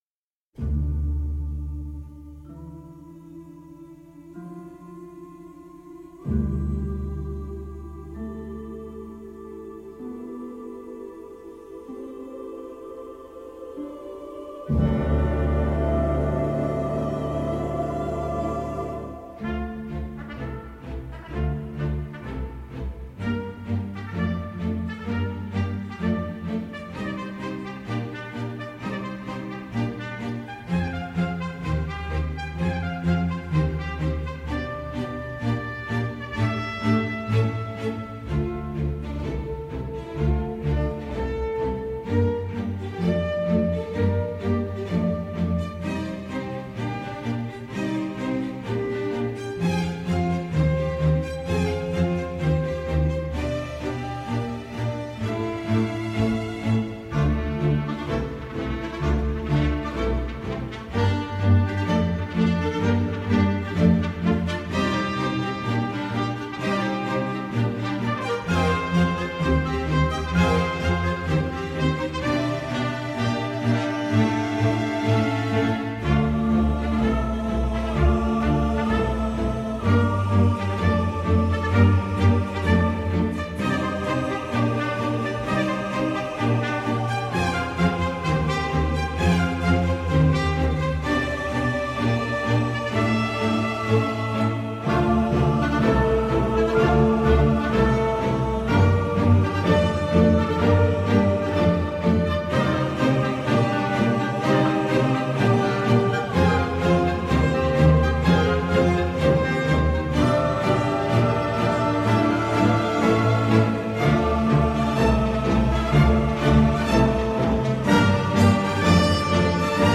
à la fois aventureux et délicats.
surtout son thème principal porté par une trompette altière.